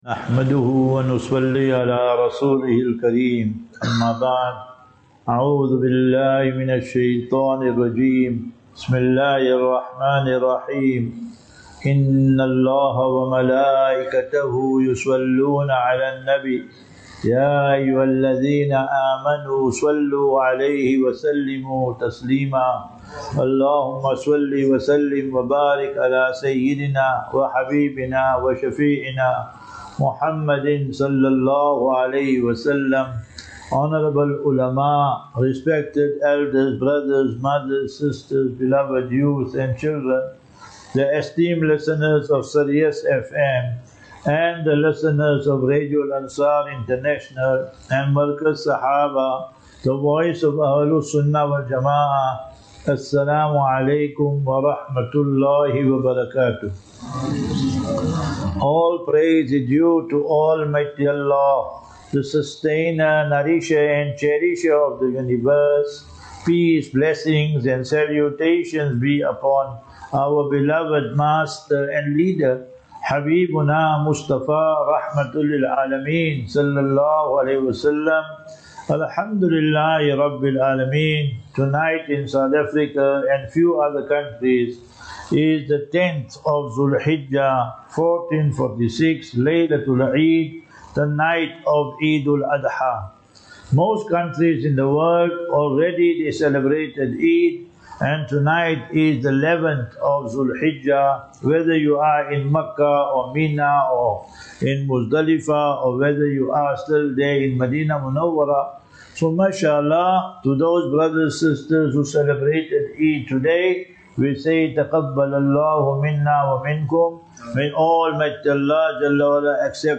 Lectures 6 Jun 06 June 2025